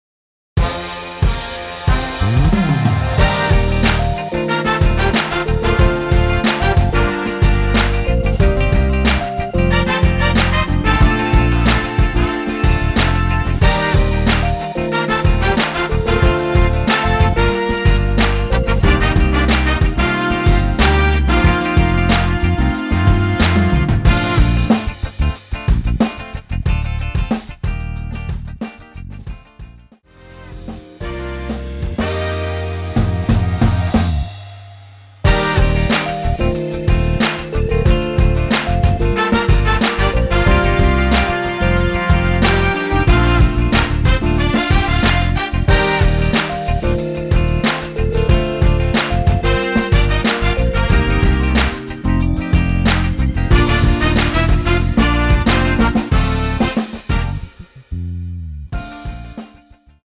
Bb
◈ 곡명 옆 (-1)은 반음 내림, (+1)은 반음 올림 입니다.
앞부분30초, 뒷부분30초씩 편집해서 올려 드리고 있습니다.
중간에 음이 끈어지고 다시 나오는 이유는